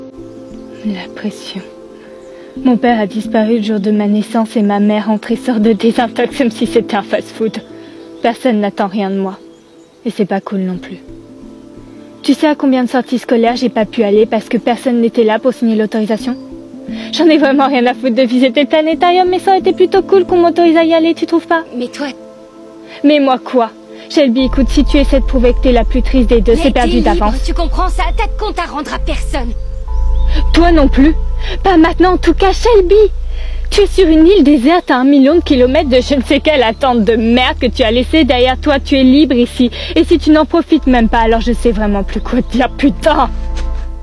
Voix jeune adulte le stylo